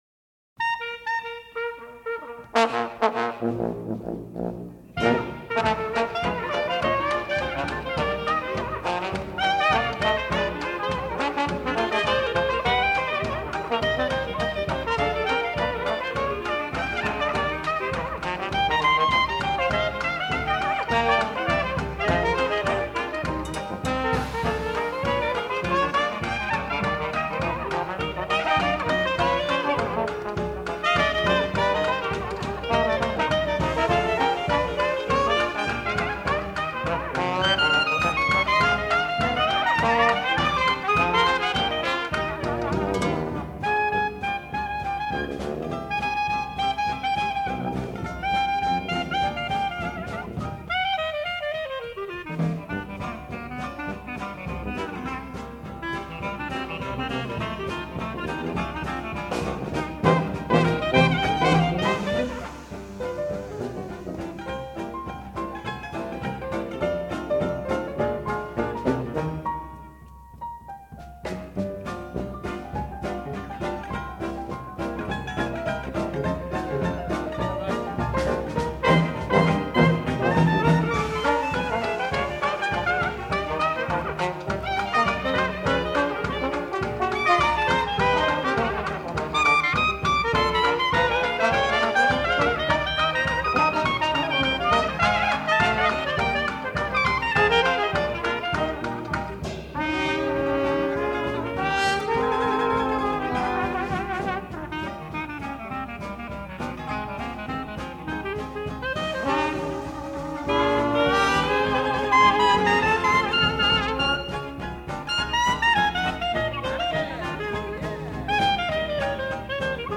on cornet and the vocals
trombone
clarinet and sax
piano
drums
banjo